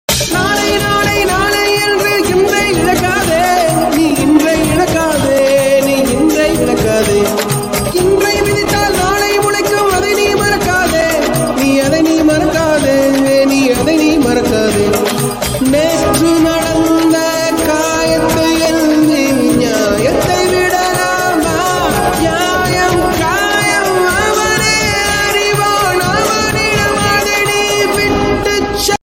best flute ringtone download
melody ringtone romantic ringtone